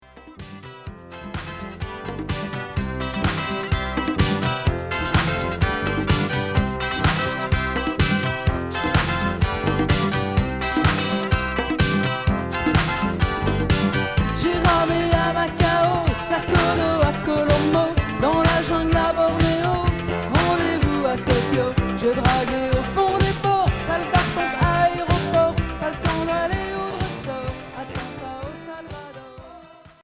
Pop variété